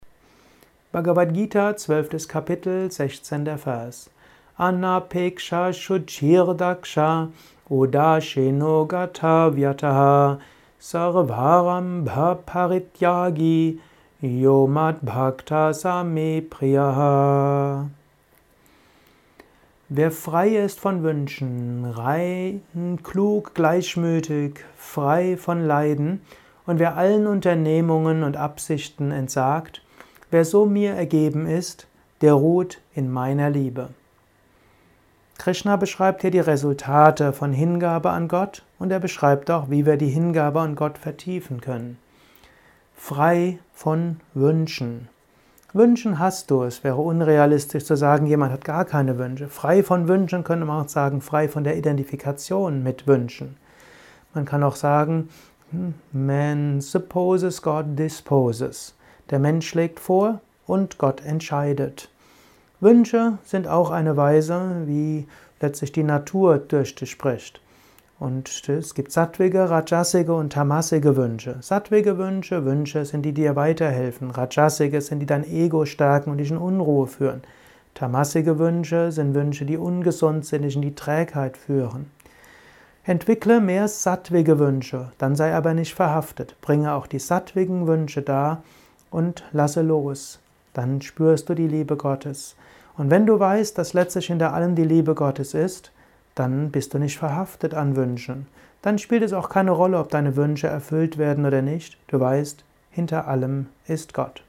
Kurzvorträge